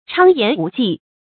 昌言无忌 chāng yán wú jì
昌言无忌发音